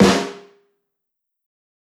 SNARE_PAYME.wav